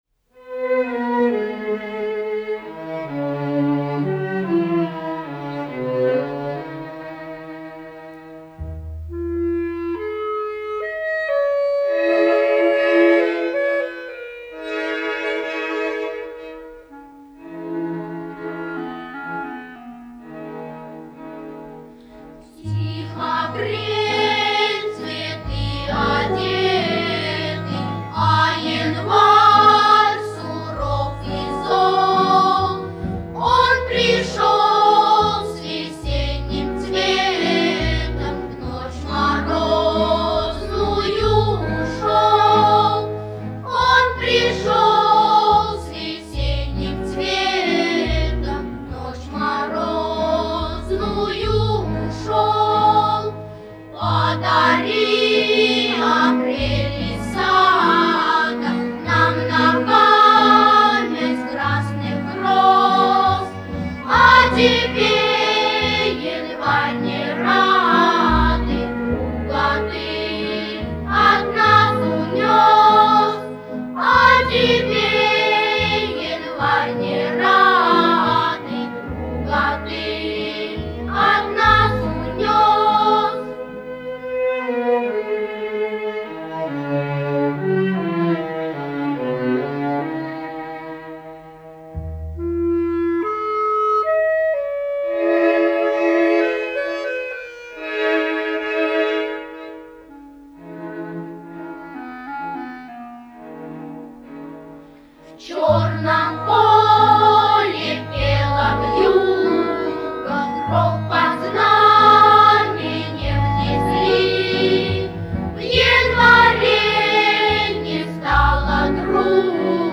Дети поют о похоронах Ленина. Улучшение качества звука.